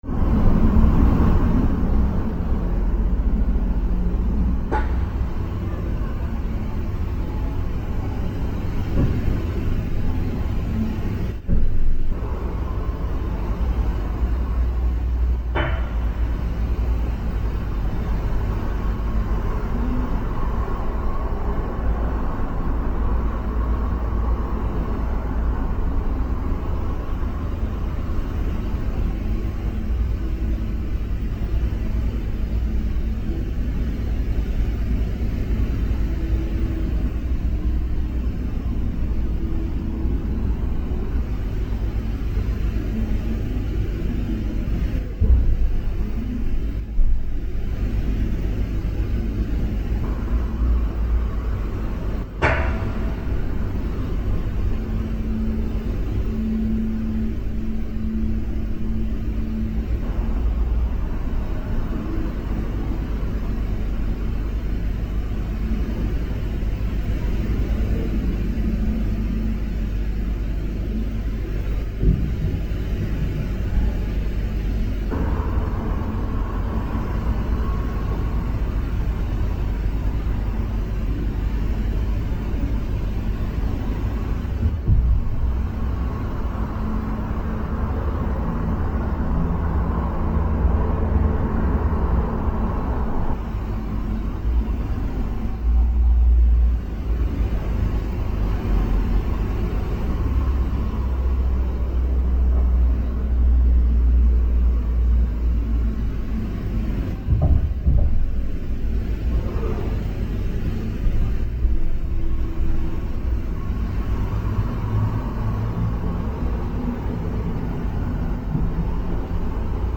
– L’esame dei materiali sonori, ha prodotto una raccolta di interessanti rumori, registrati a casa vuota e chiusa in nostra assenza.
L’ esame spettrometrico delle fonti sonore, ha richiesto parecchio lavoro di filtraggio tramite equilizzatore parametrico e filtri ricostruttivi, a causa della vicinanza di una strada estremamente trafficata. Nonostante porta e finestre chiuse è sovrastante il rumore della strada.
I più evidenti sono riportabili ad un effetto di assestamento dei termosifoni presenti nei locali, ma sono molto spesso percepibili passi e rumori vicini alla fonte di registrazione.
L’ enfatizzazione estrema delle frequenze estremamente basse e di quelle estremamente alte, potrebbe comunque riportare i particolari suoni, come provenienti da appartamenti vicini o passaggio di persone nella rampa di scale a qualche metro di distanza.